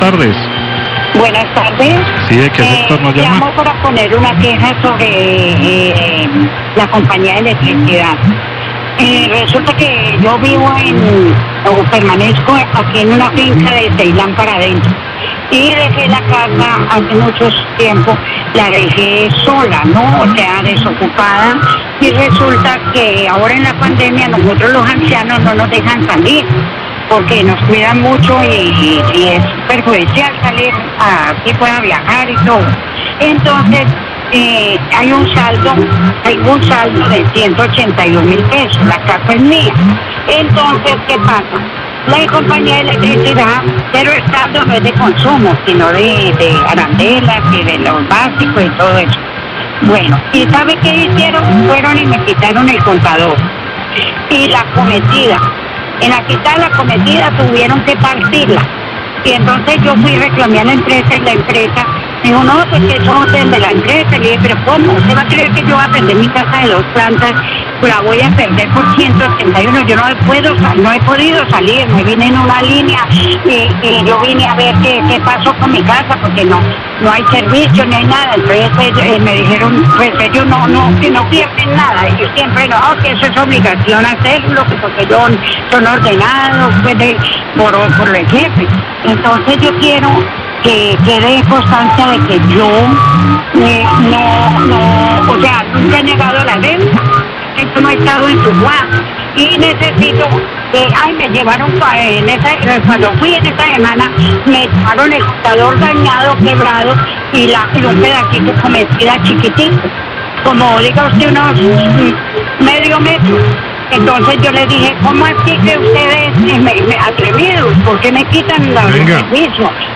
Queja de oyente porque le quitaron el medidor de energía sin avisar, La Cariñosa, 1245pm
Radio